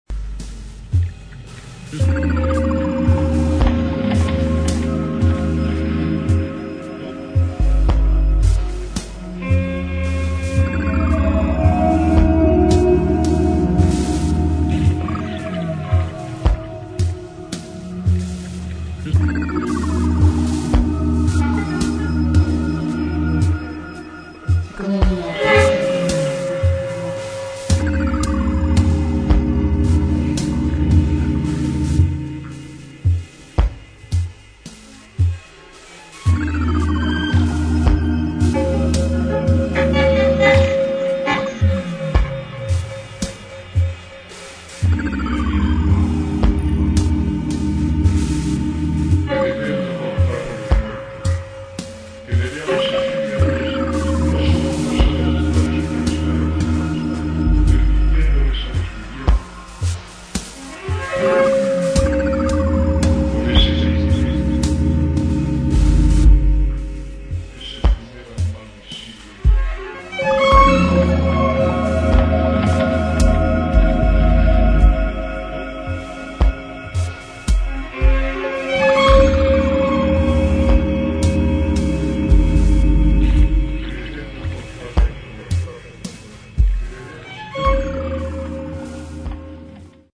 [ ELECTRONIC / EXPERIMENTAL / AMBIENT ]